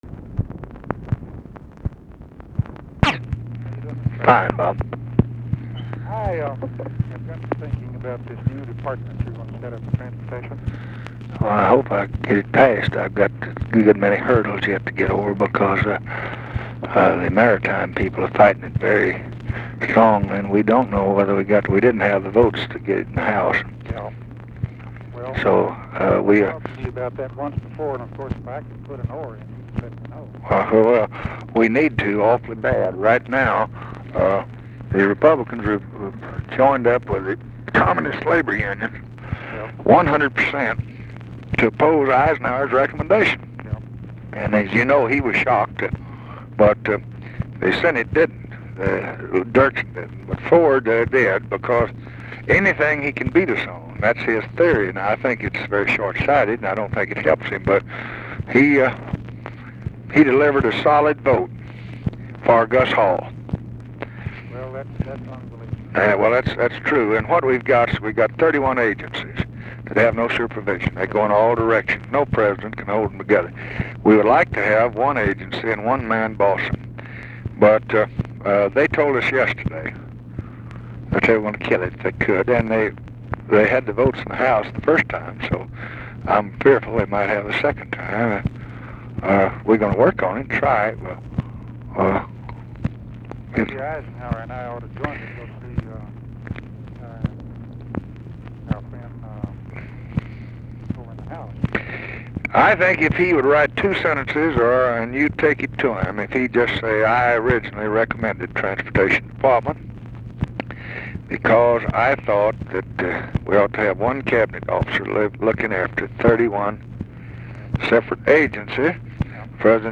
Conversation with ROBERT ANDERSON, October 5, 1966
Secret White House Tapes